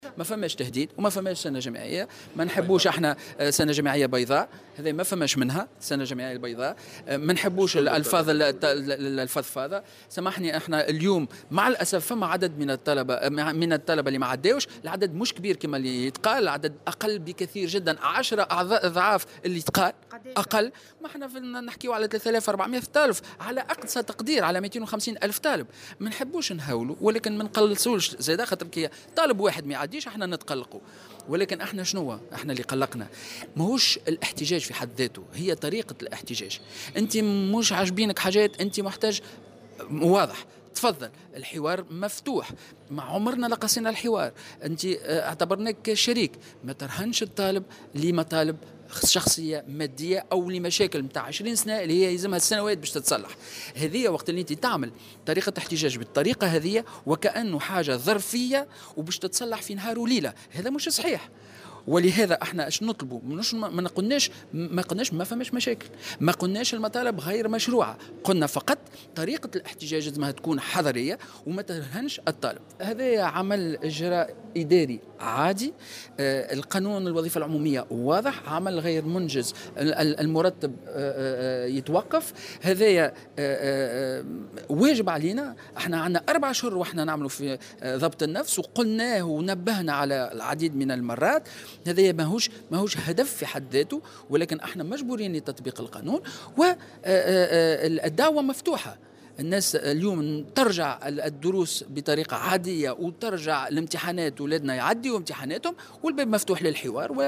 وأضاف في تصريح اليوم لمراسلة "الجوهرة أف أم" على هامش مؤتمر"حول تشغيلية الطلبة ومراكز المهن واشهاد الكفاءات" بالحمامات، أن الإجراء المتعلّق بتجميد أجور الاساتذة الجامعيين المضربين إجراء قانوني، وفق تعبيره.